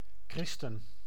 Ääntäminen
IPA: /ˈkrɪs.tən/ IPA: /ˈχrɪs.tən/